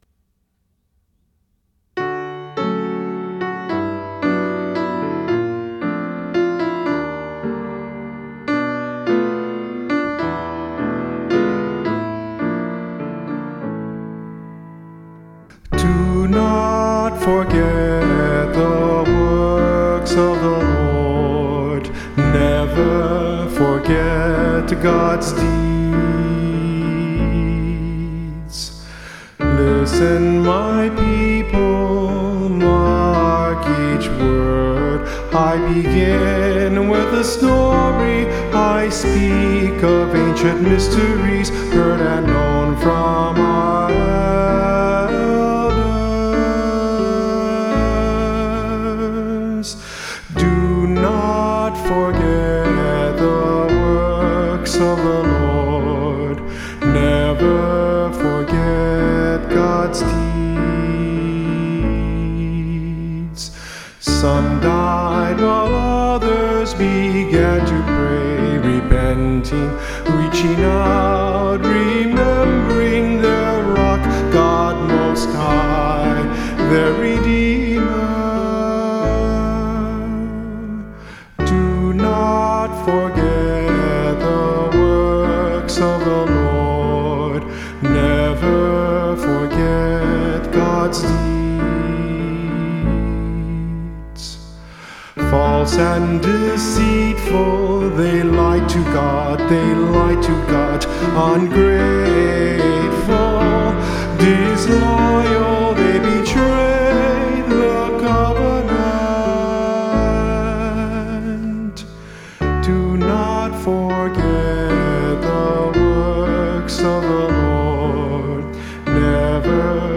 Instrumental | Downloadable